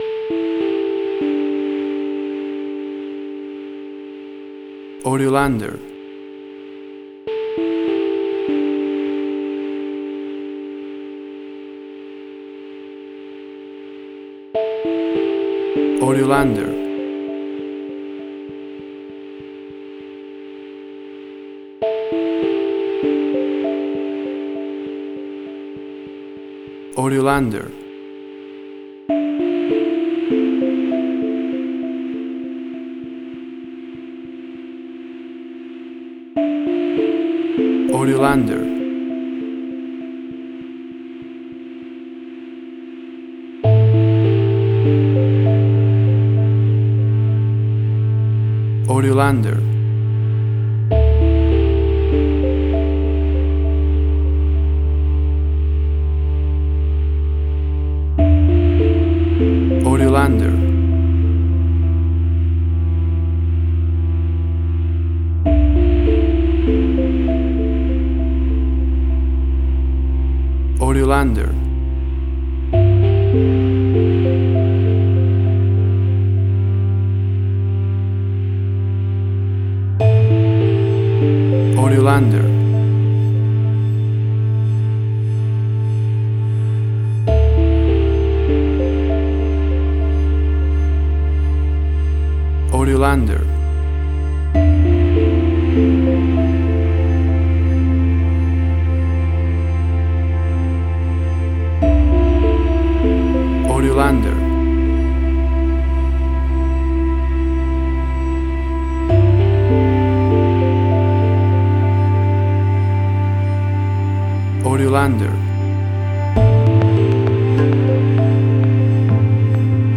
IDM, Glitch.
Tempo (BPM): 100